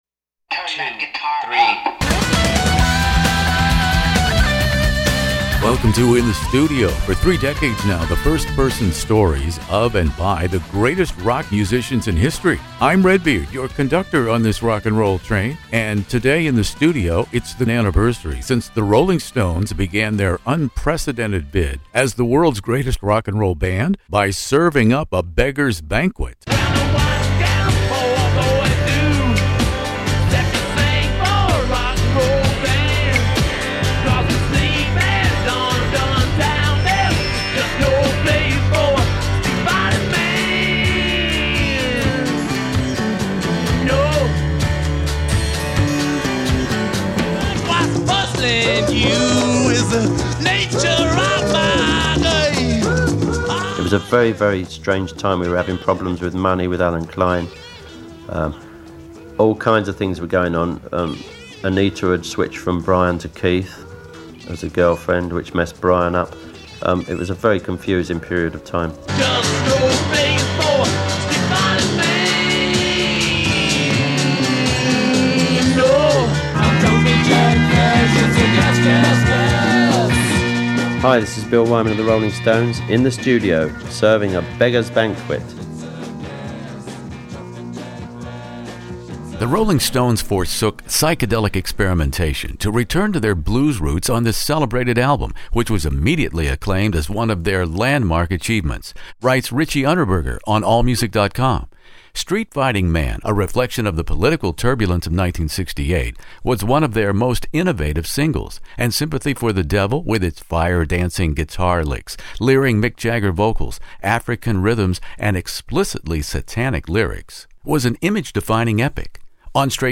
The 50th anniversary interview about the Rolling Stones' "Beggars Banquet" with Mick Jagger, Keith Richards, & original Stones bass player Bill Wyman.